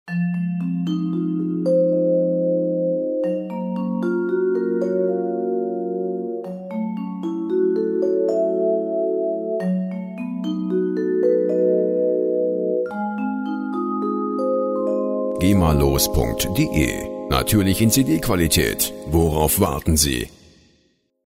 Schlaginstrument